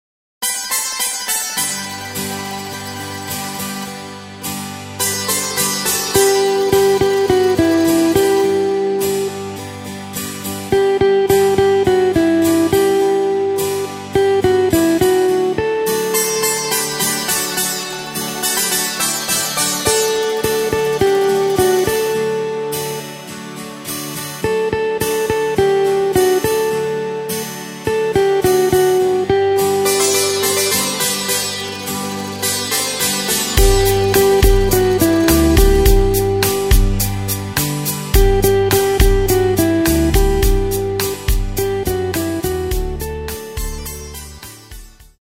Takt:          4/4
Tempo:         105.00
Tonart:            G
Schlager aus dem Jahr 2002!